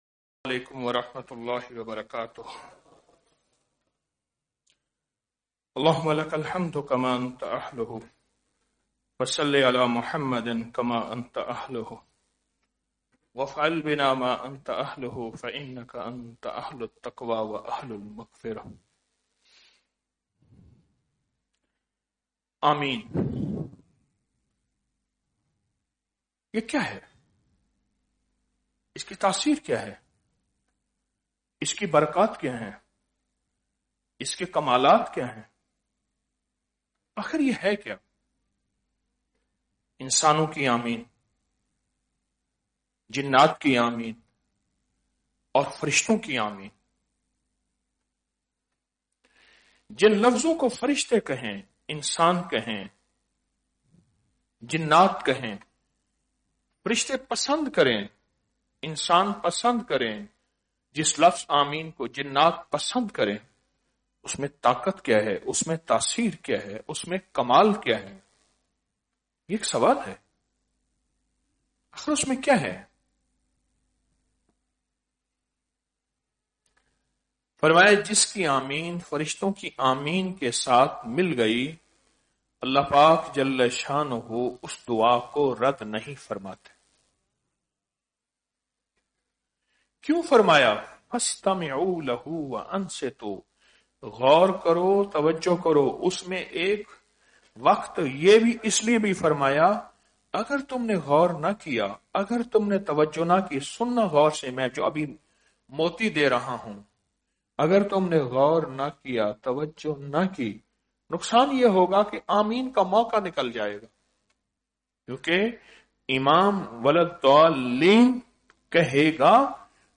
درس